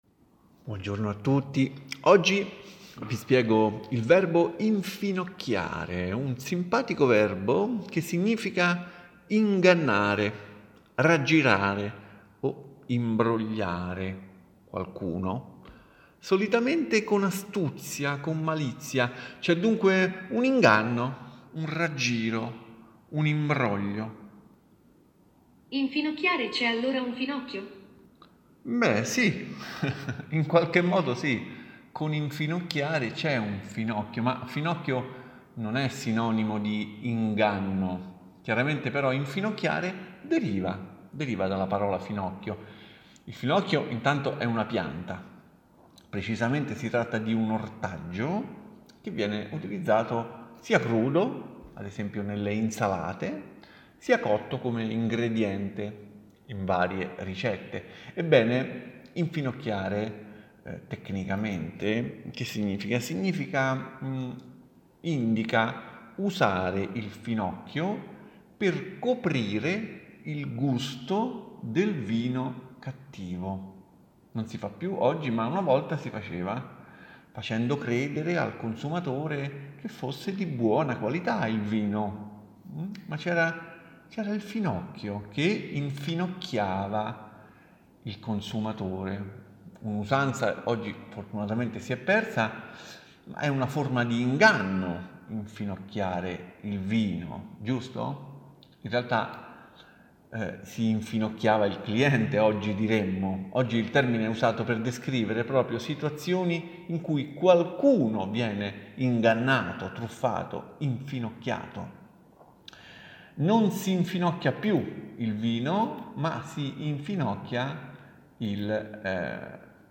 L’inizio e/o la fine di ogni episodio dei “due minuti con italiano semplicemente” servono a ripassare le espressioni già viste e sono registrate dai membri dell’associazione.